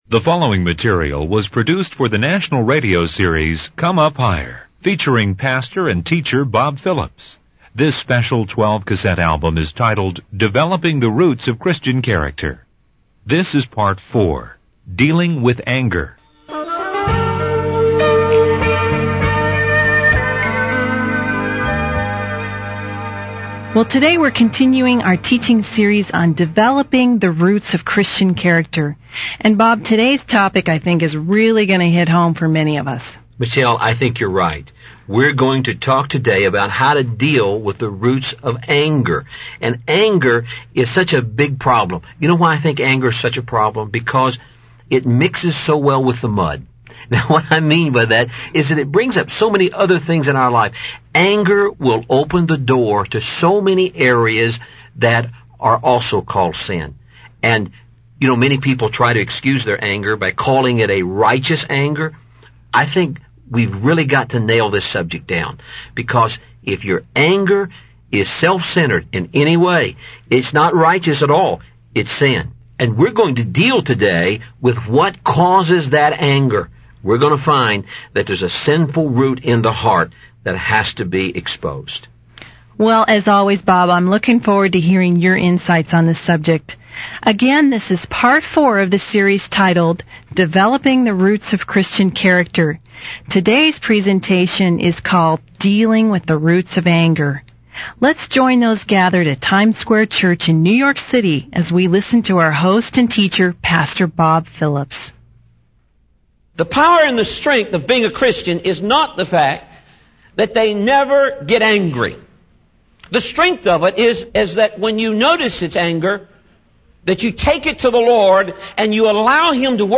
In this sermon, the preacher emphasizes the importance of obeying God with all of our heart and soul. He explains that God's commandments are not too difficult or out of reach for us to follow.